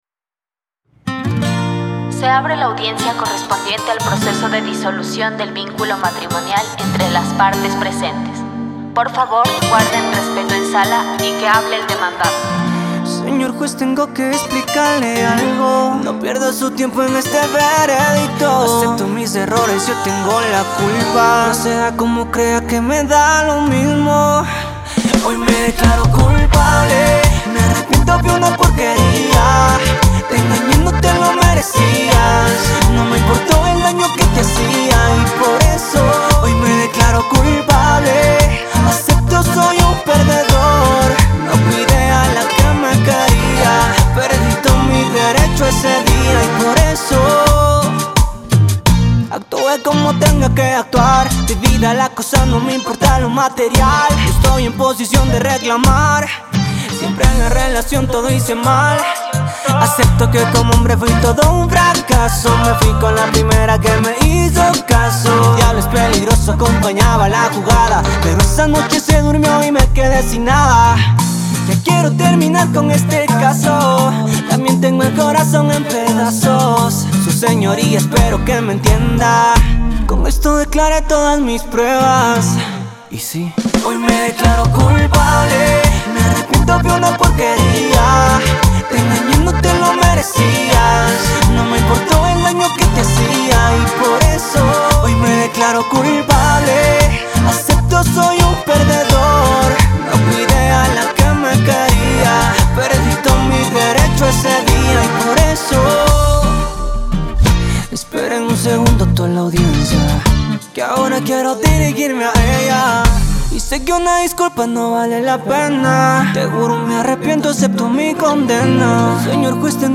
esta canción logra equilibrar lo urbano con lo sentimental